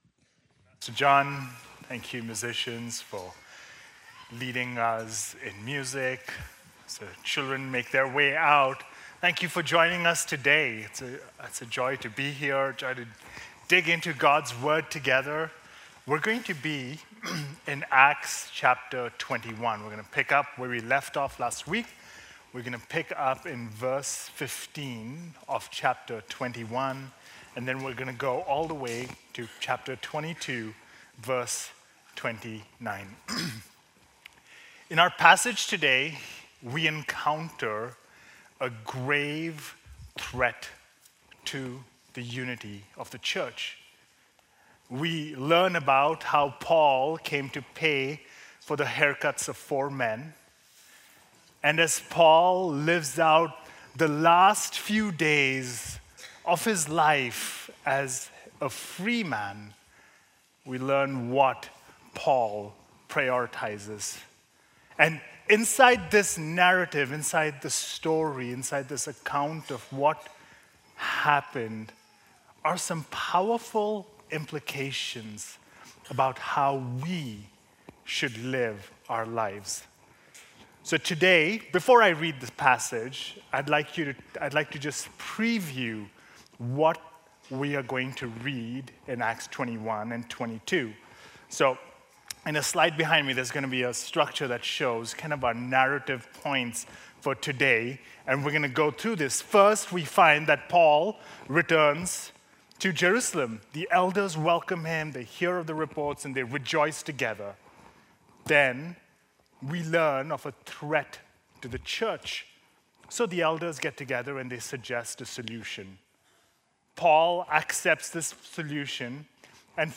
Gospel Grace Church Sermon Audio